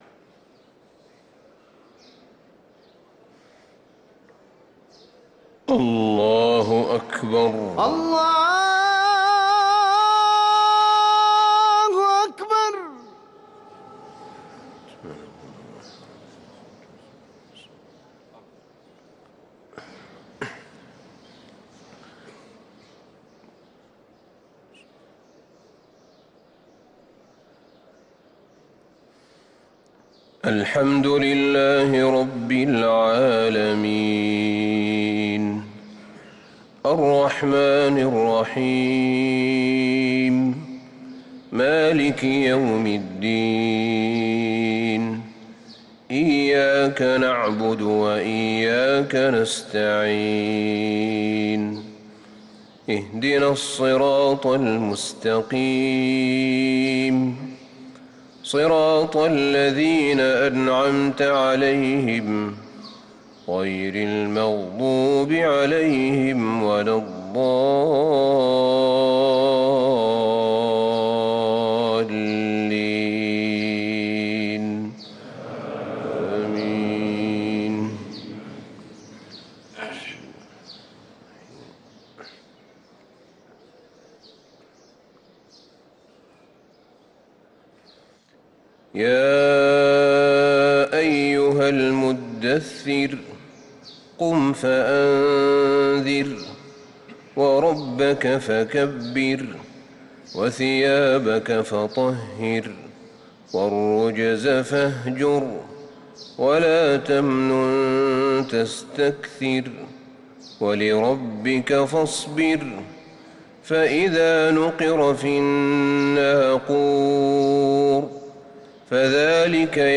صلاة الفجر للقارئ أحمد بن طالب حميد 4 شعبان 1445 هـ
تِلَاوَات الْحَرَمَيْن .